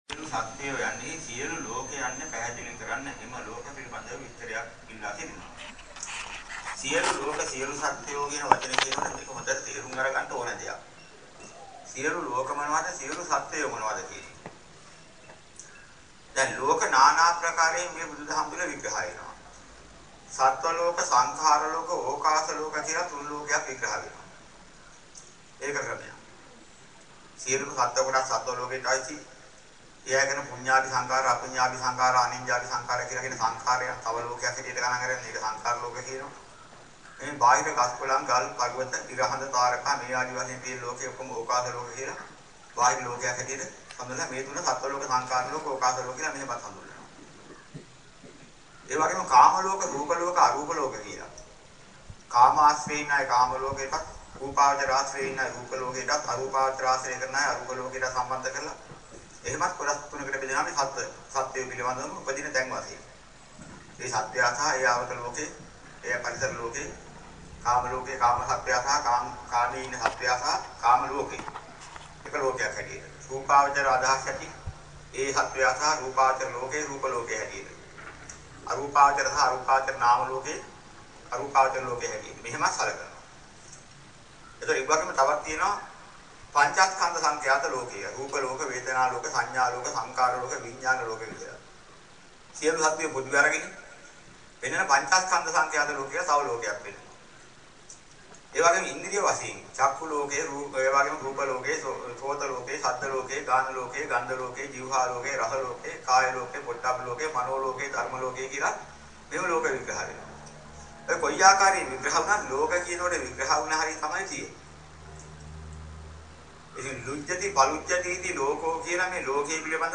දේශනාවට සවන් දෙන්න (අහන ගමන් කියවන්න)